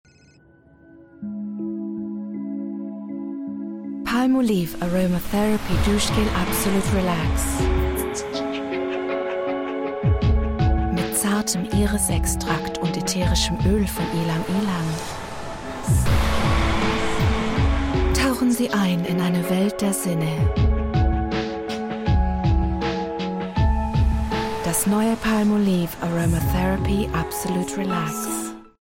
German. Actress, light, informative, friendly.